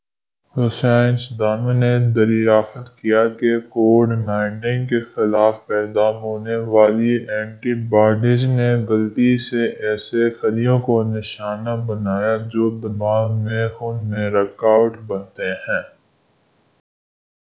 deepfake_detection_dataset_urdu / Spoofed_TTS /Speaker_17 /111.wav